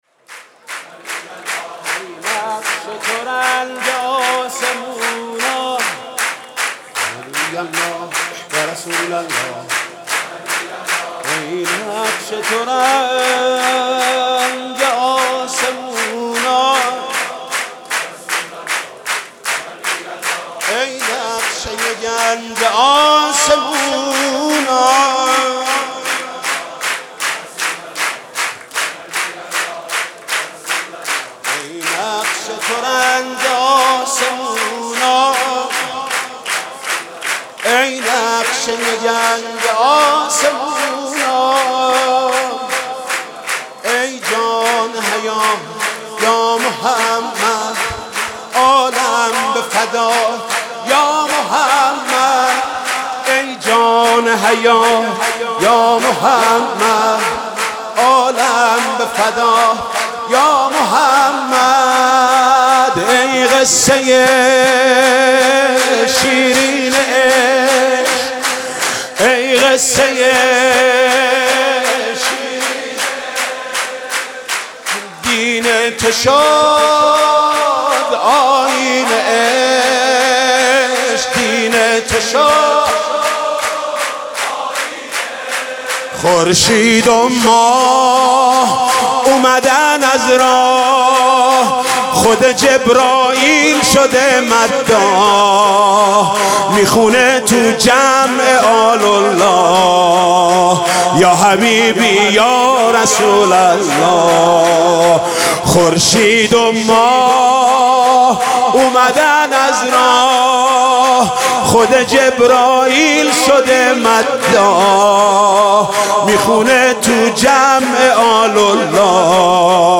سرود: ای نقش ترنج آسمونا